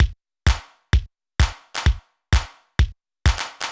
TEC Beat - Mix 4.wav